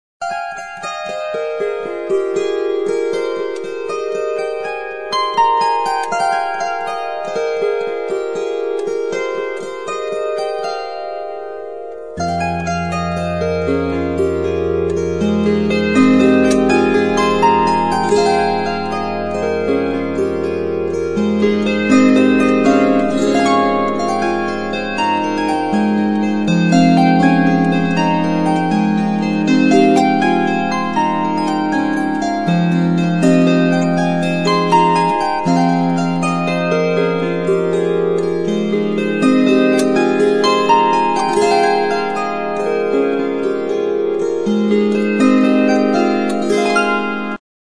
Cordes -> Pincées
Celtic Harp.